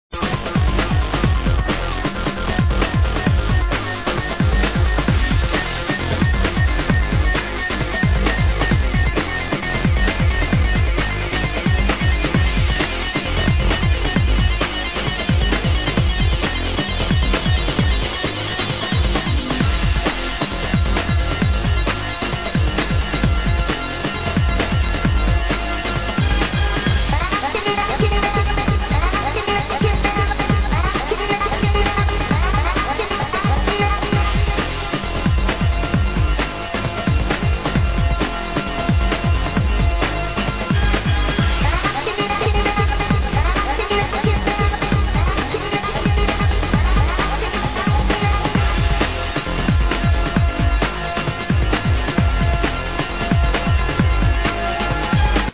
Question Unknown remix from 95 or so.
I think this is a remix of a tune that has vocals sounding something like: